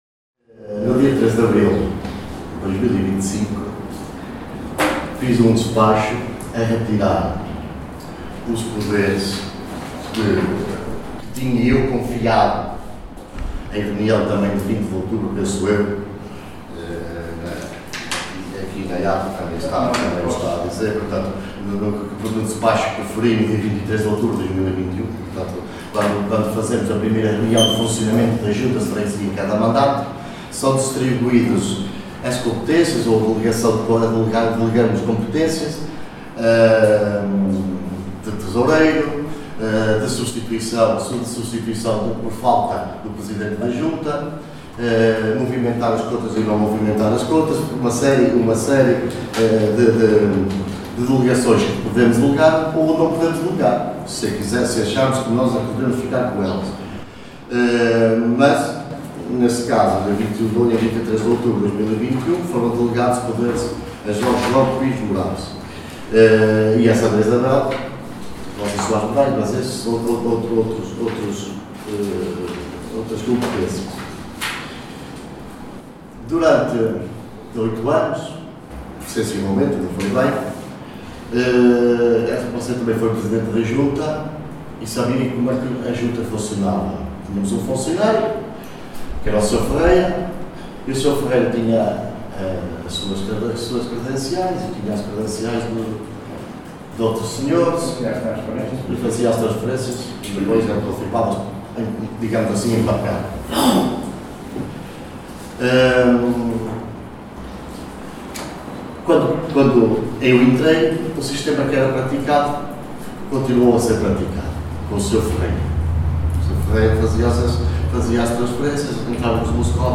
Mário Morgado, em resposta, explicou aos presentes o porquê deste comunicado ter vindo a público para a comunicação social.